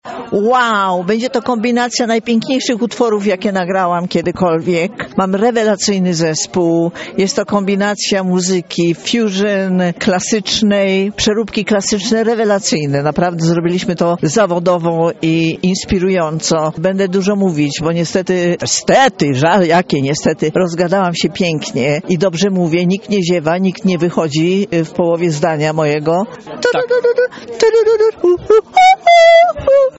Jestem przeszczęśliwa, że mogę zaśpiewać w Lublinie – mówi Urszula Dudziak i mówi o tym co usłyszymy podczas dzisiejszego koncertu: